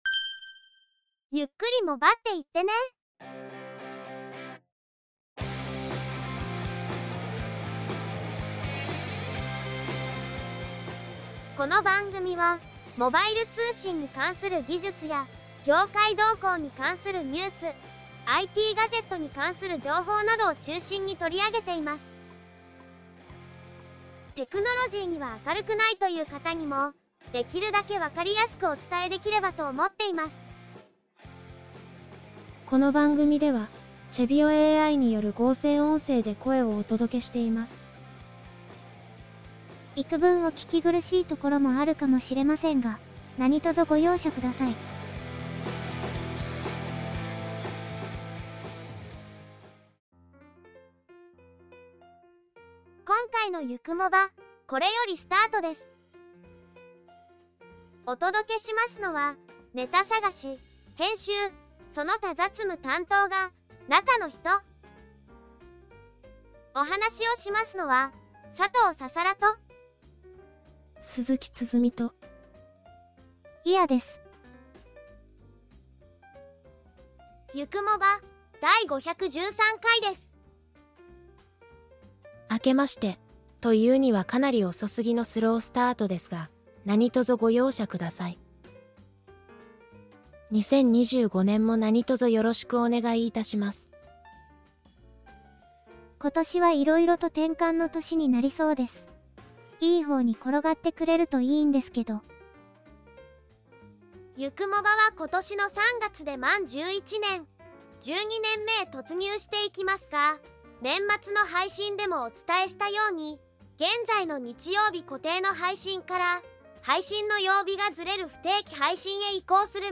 全編合成音声によりお届けいたします。